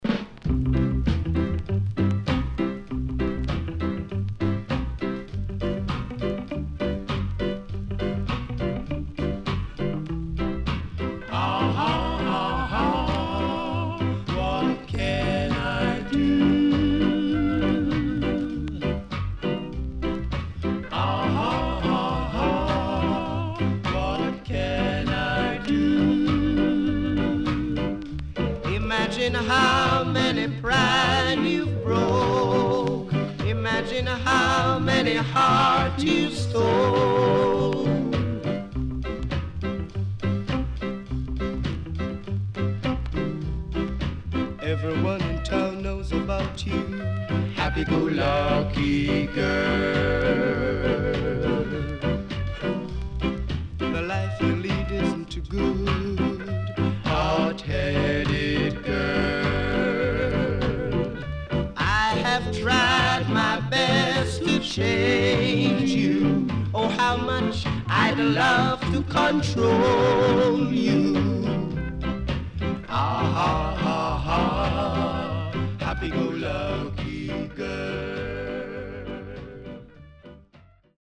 Reissue Used